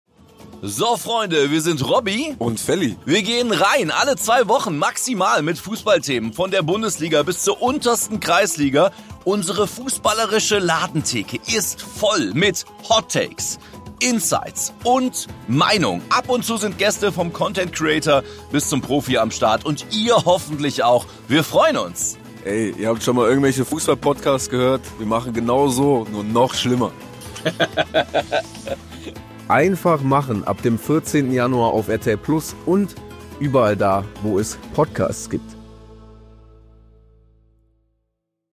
Trailer: Einfach Machen